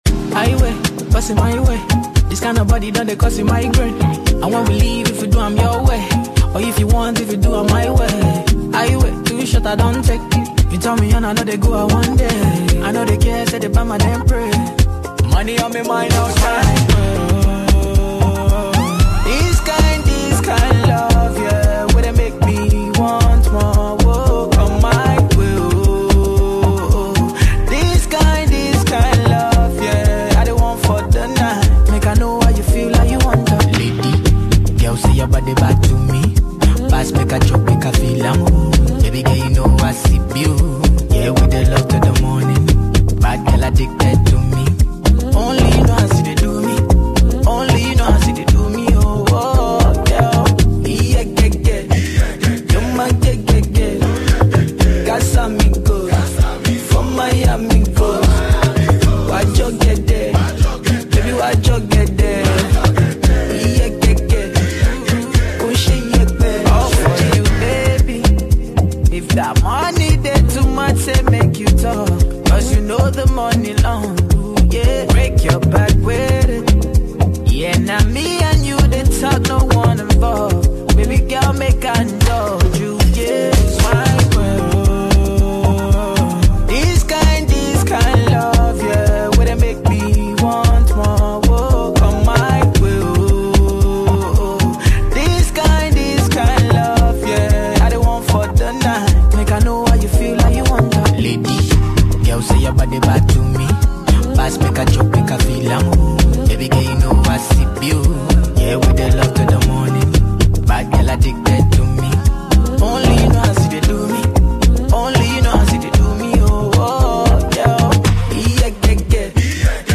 this Afrobeats mix is perfect for anyone who